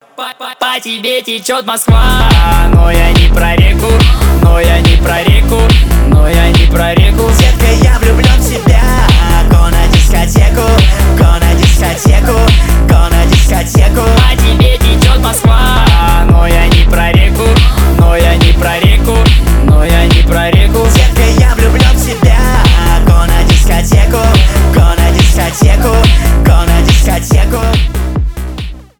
танцевальные , дуэт , поп